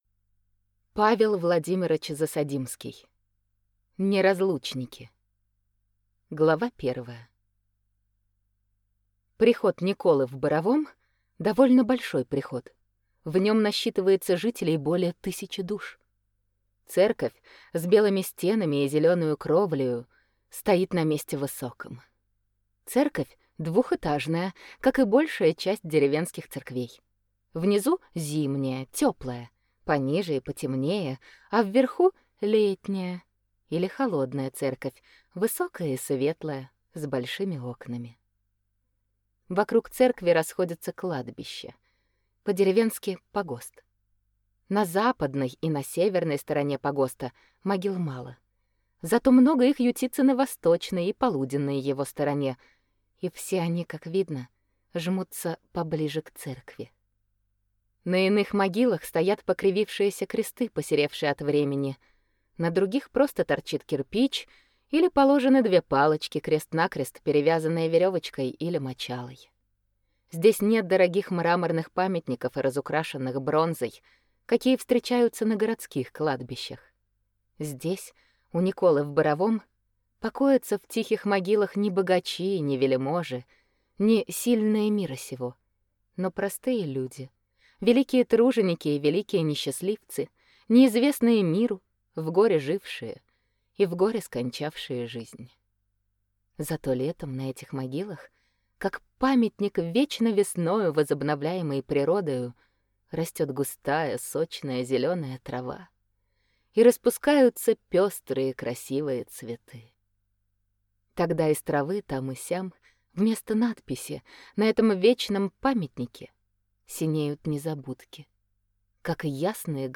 Аудиокнига Неразлучники | Библиотека аудиокниг